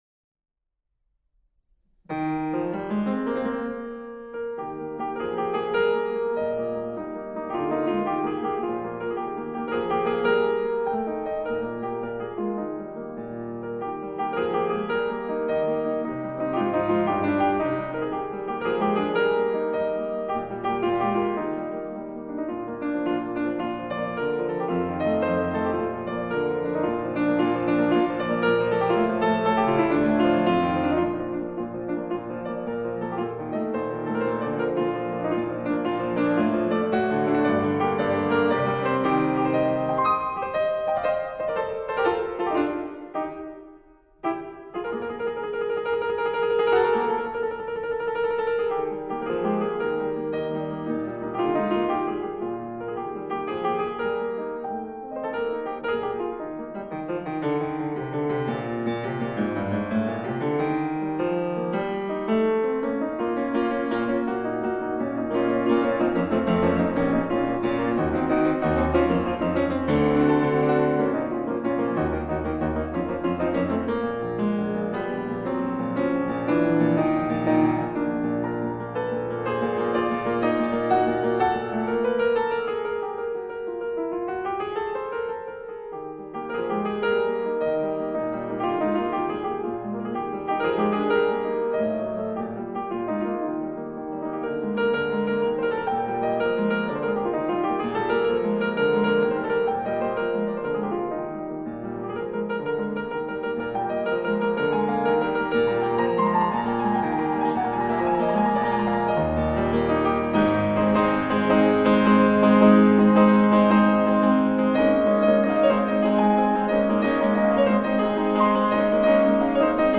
Rondo giocoso.